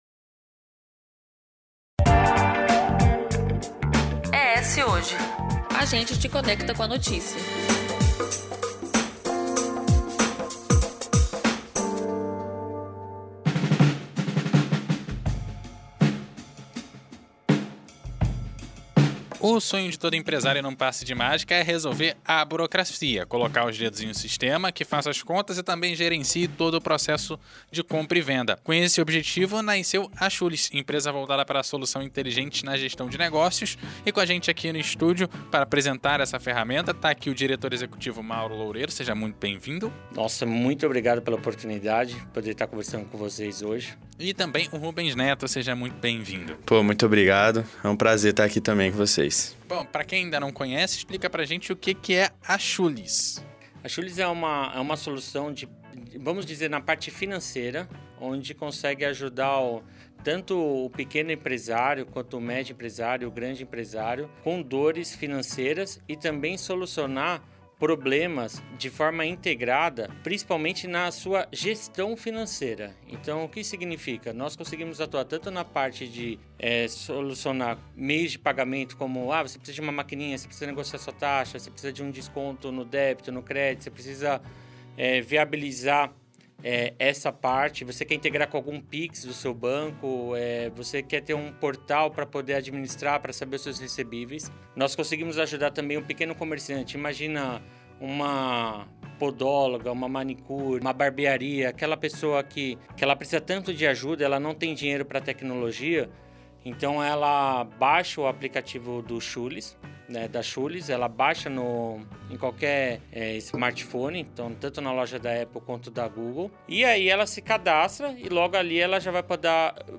Recebemos no estúdio